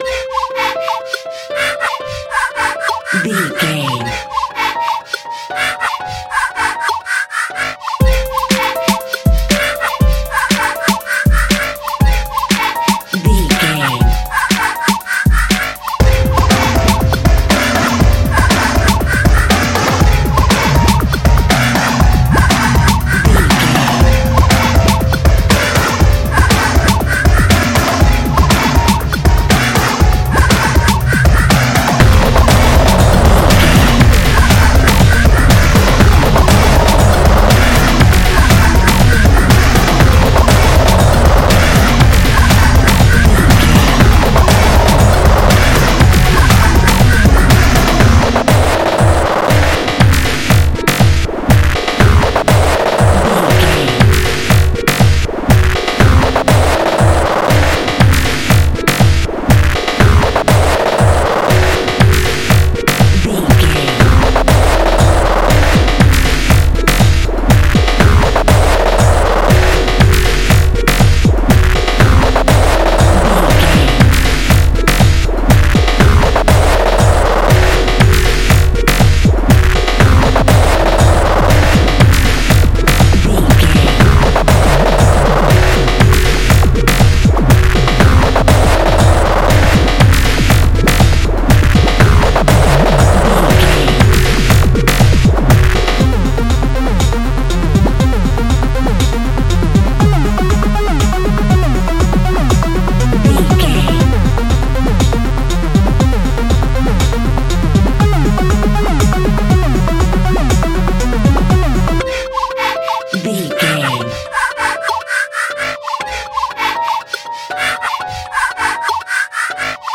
Epic / Action
Fast paced
Atonal
hard
intense
energetic
driving
aggressive
dark
piano
synthesiser
drums
drum machine
breakbeat
synth leads
synth bass